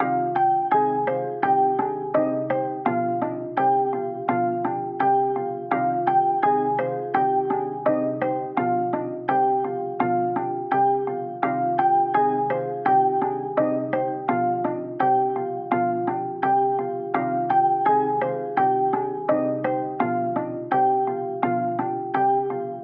拨动合成器里夫9
描述：C小调的弹拨式合成器乐曲。延迟的
Tag: 168 bpm Trap Loops Synth Loops 3.85 MB wav Key : C Ableton Live